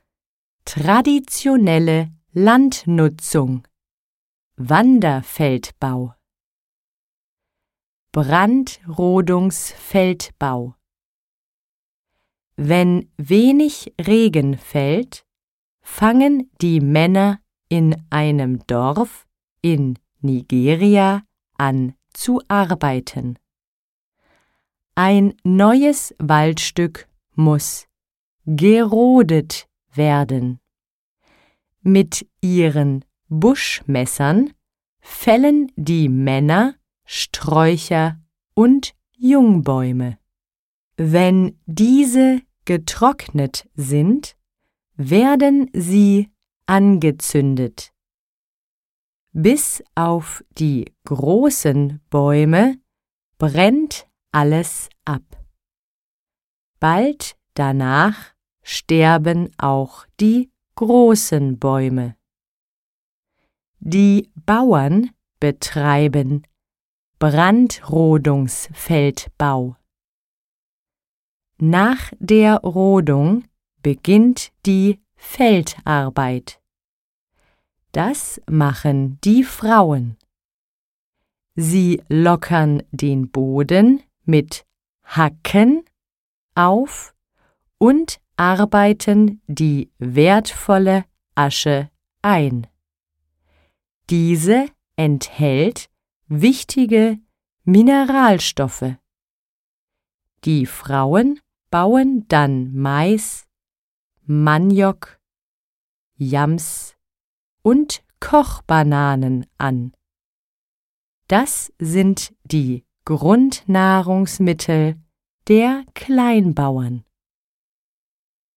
Sprecherin: